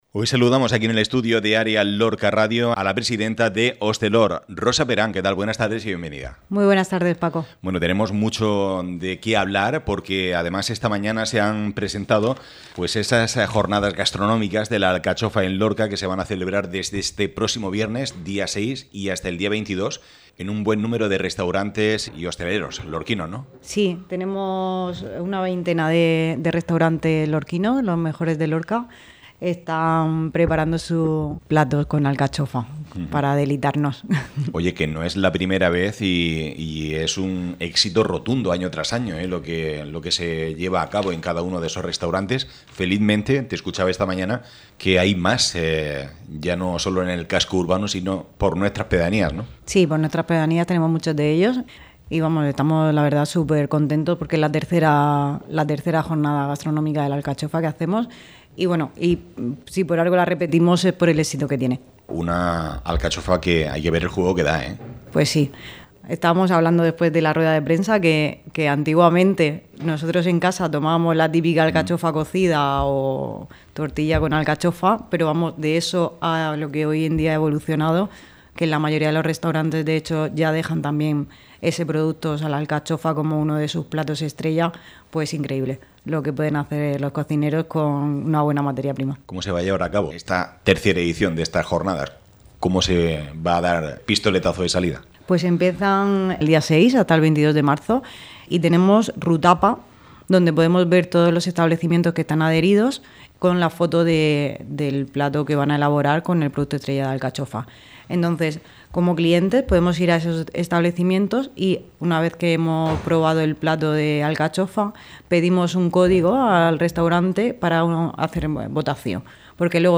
Actualidad.